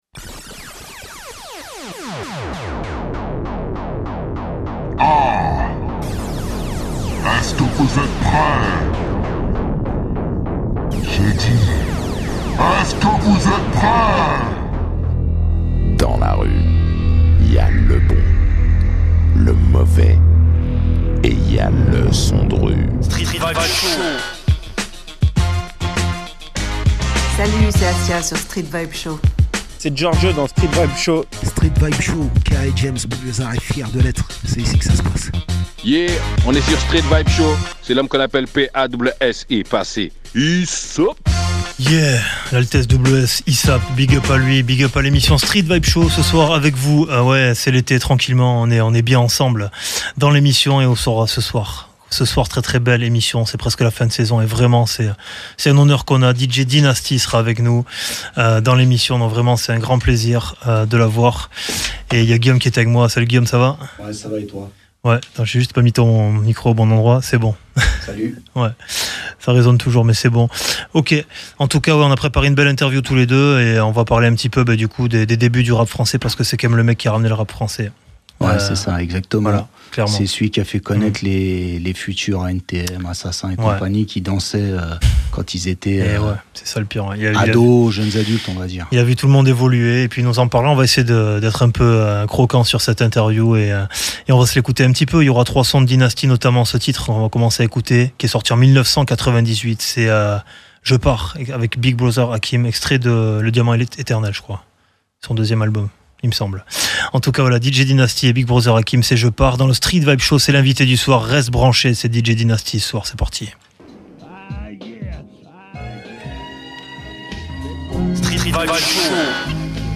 Hip Hop